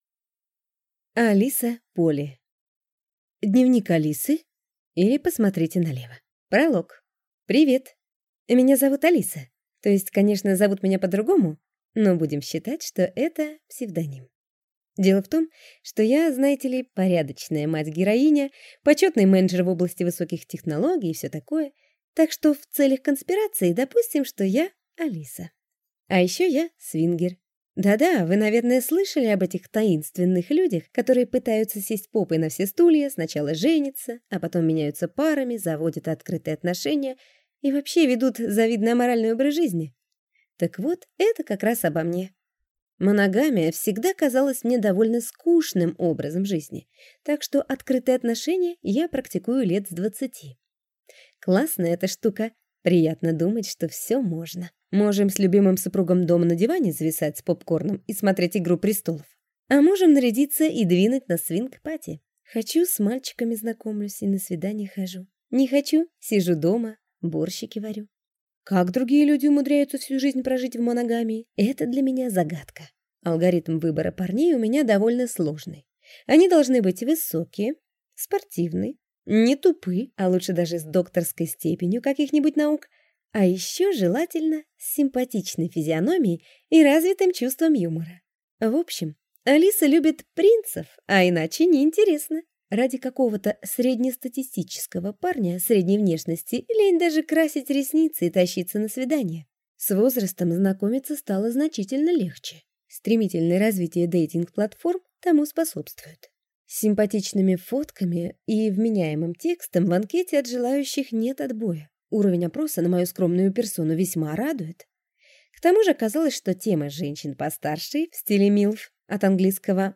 Аудиокнига Дневник Алисы, или Посмотрите налево | Библиотека аудиокниг
Прослушать и бесплатно скачать фрагмент аудиокниги